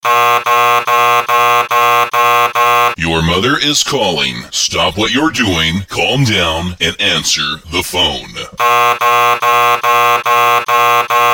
Kategorie Alarmowe